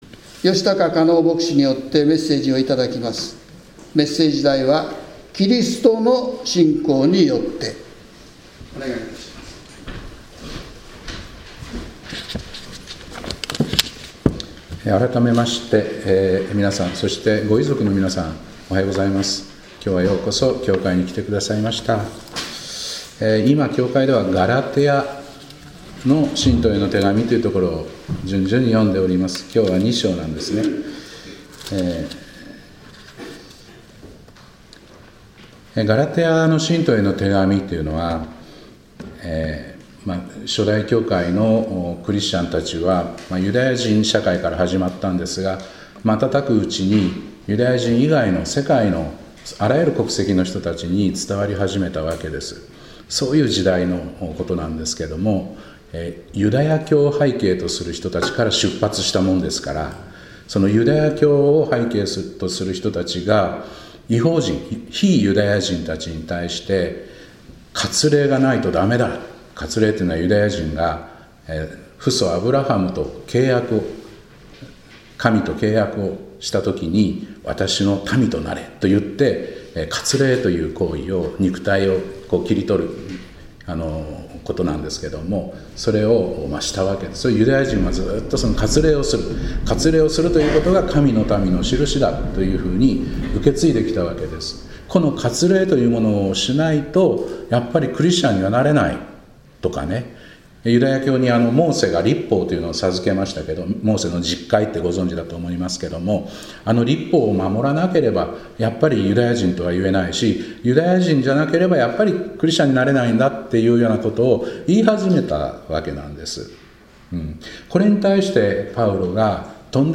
2025年5月11日礼拝「キリストの信仰によって」
今日は召天者記念礼拝です。